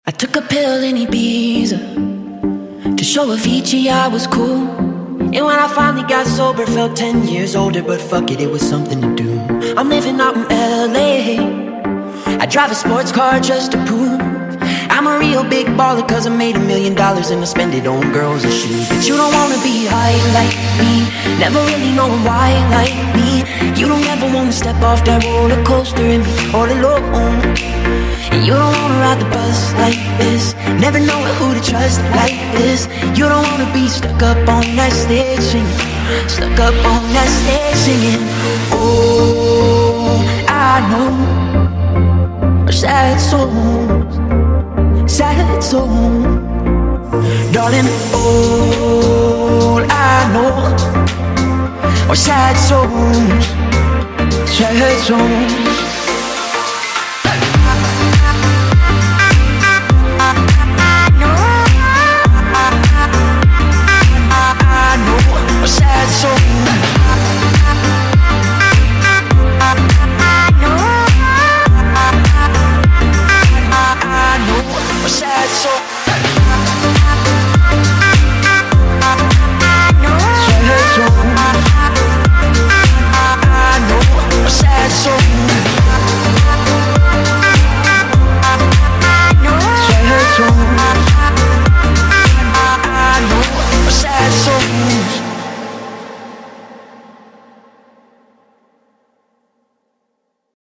BPM51-102